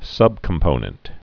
(sŭbkəm-pōnənt)